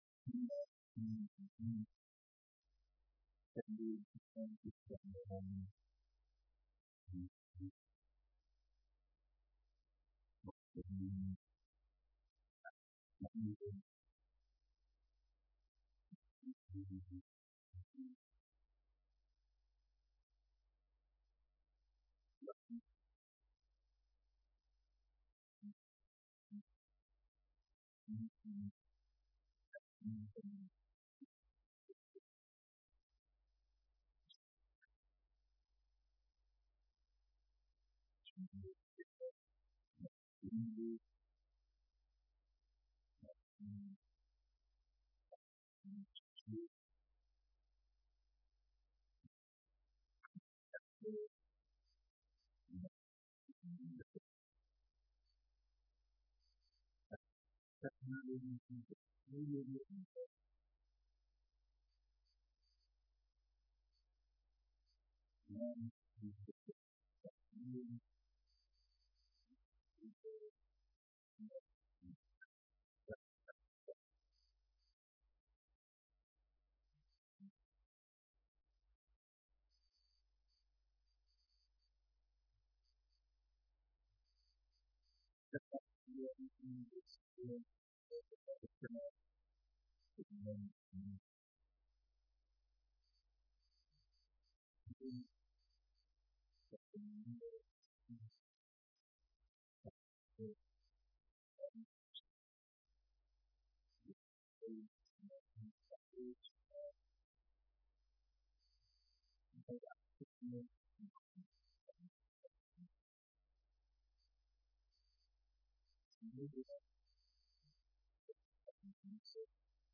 بیانات در دیدار معلمان و مسئولان آموزش و پرورش